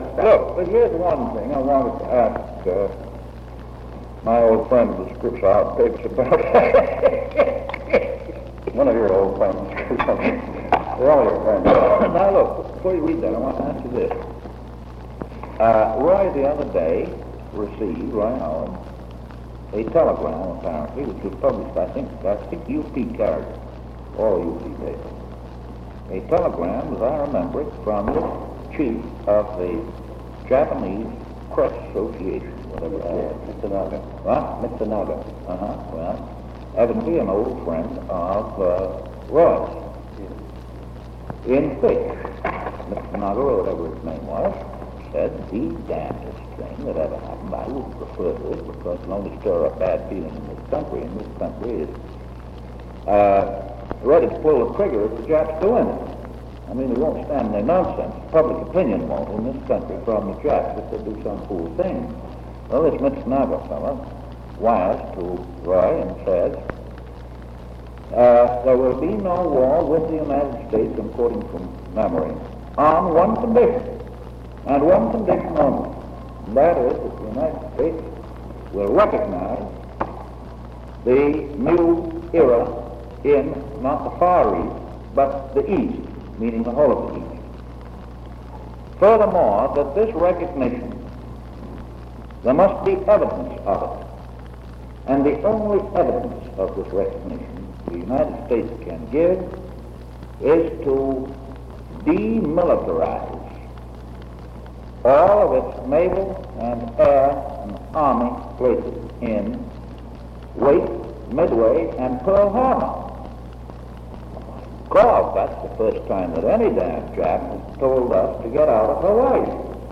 The Presidency / Featured Content 'We Are Worried, Awfully Worried' 'We Are Worried, Awfully Worried' Photo: Signal Corps Archive Following a press conference, President Roosevelt spoke privately about the crisis in the Far East, where the British were about to begin the resupply of Chinese forces resisting Japanese occupation. Distressing reports from Japan suggested that the only way to avoid a regional war would be for the United States to vacate its bases throughout the Pacific—including Pearl Harbor. Date: October 8, 1940 Location: Executive Offices of the White House Tape Number: Press Conference 688 Participants Franklin D. Roosevelt Sr. Walter Winchell Associated Resources Annotated Transcript Audio File Transcript